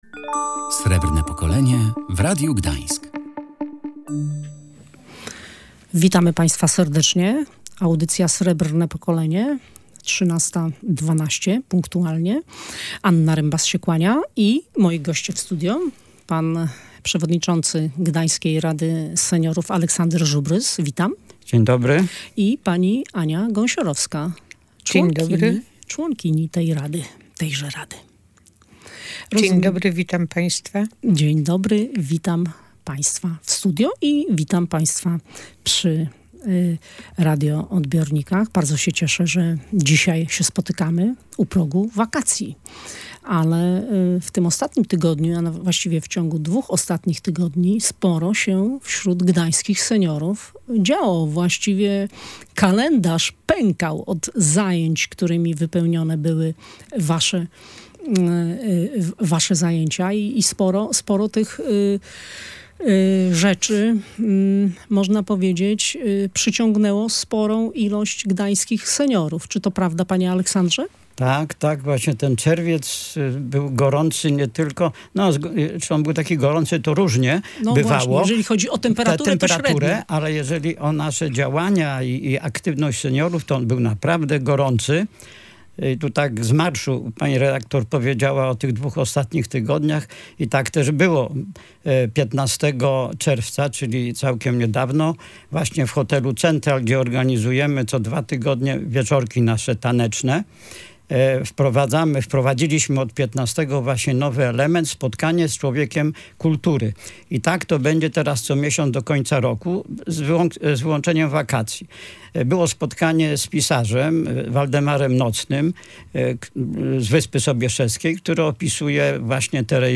Rozmowa z członkami Gdańskiej Rady Seniorów